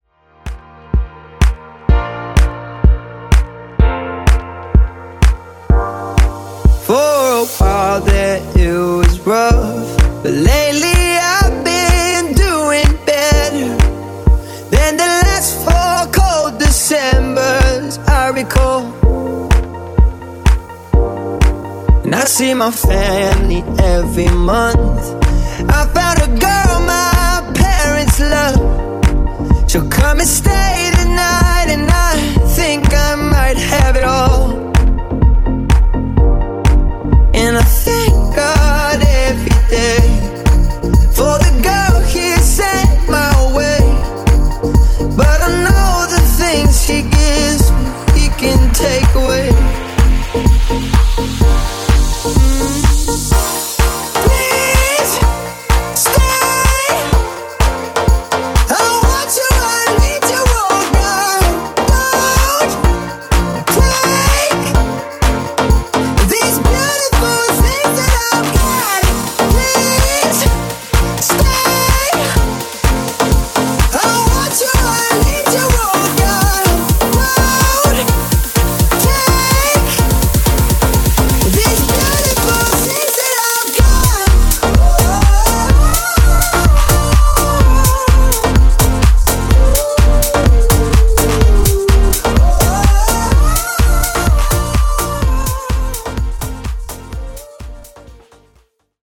Classic Redrum)Date Added